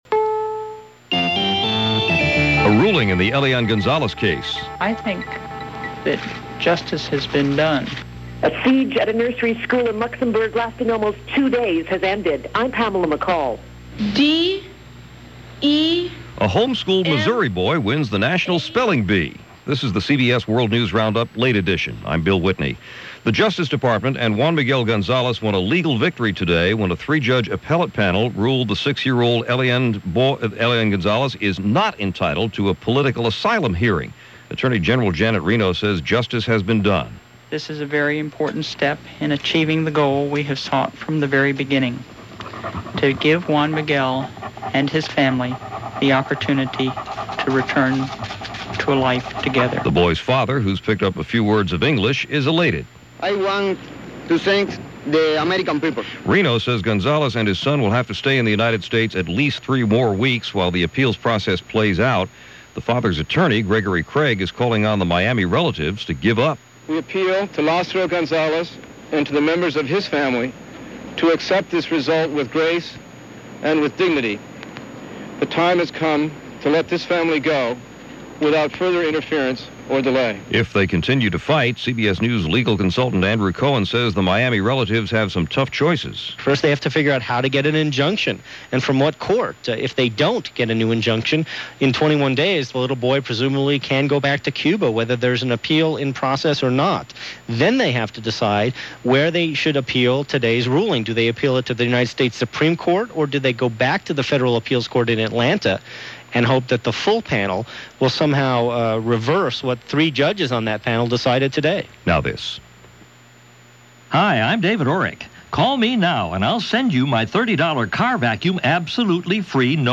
And that’s a small sample of what went on, this June 1st in 2000, as presented by The CBS World News Roundup.